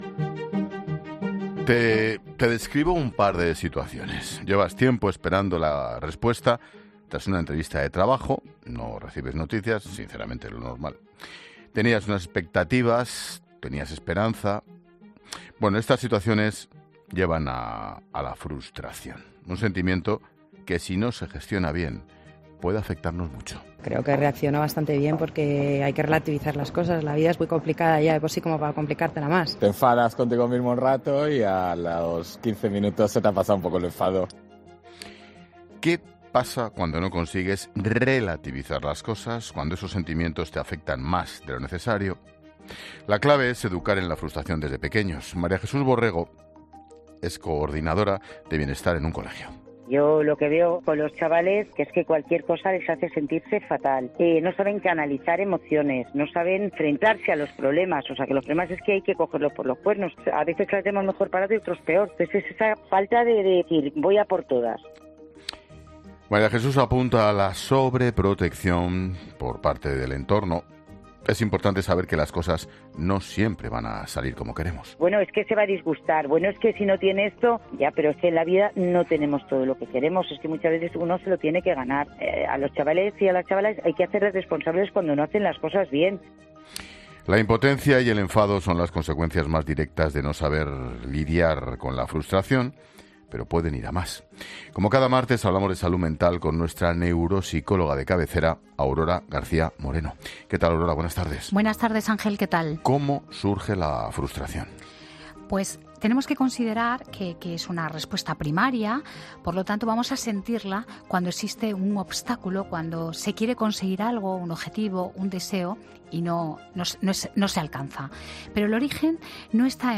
La frustración puede acarrear "ansiedad, e incluso depresión", por ello en 'La Linterna' dos expertas exponen la forma para manejar este sentimiento